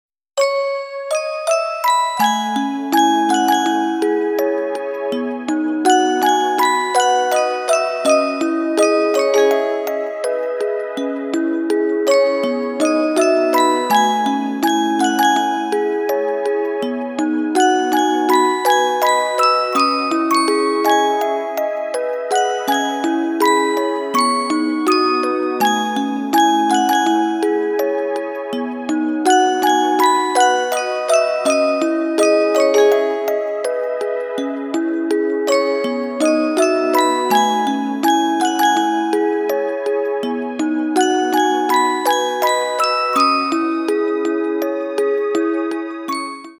• Качество: 192, Stereo
красивые
спокойные
без слов
инструментальные